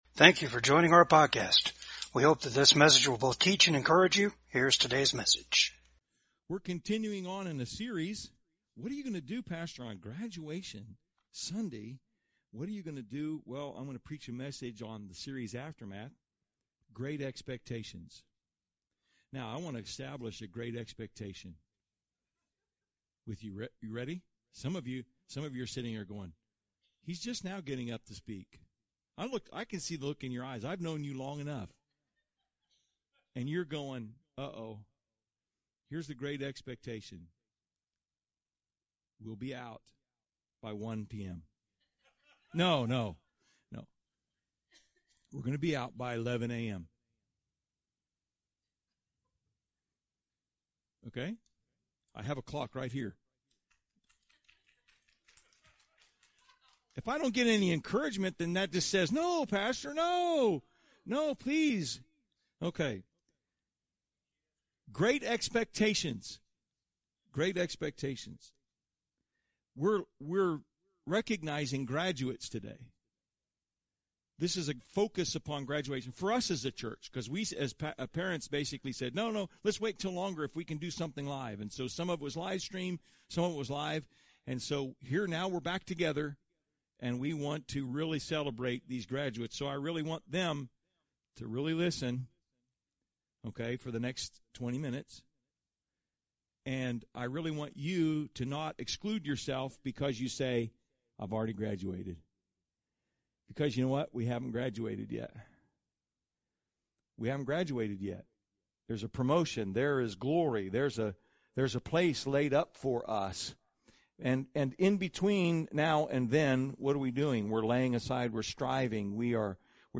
Passage: 2 Peter 1:3-11 Service Type: VCAG SUNDAY SERVICE